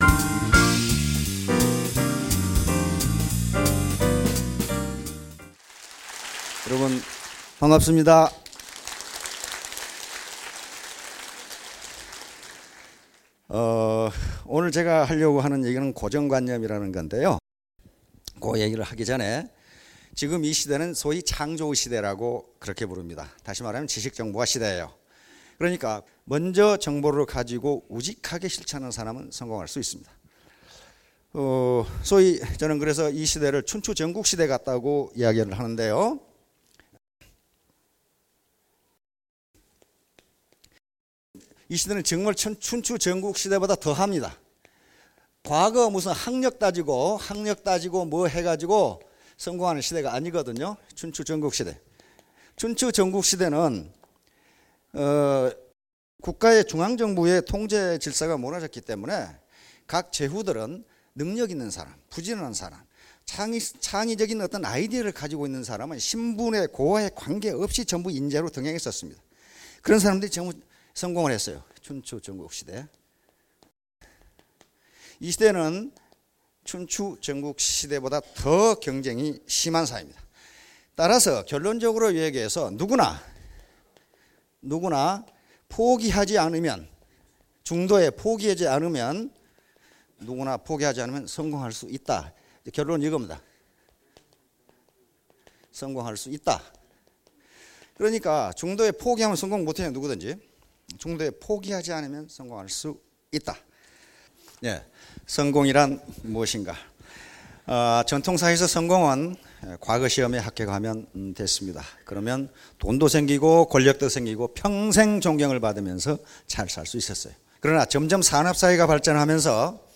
장소 : SBT 원데이 세미나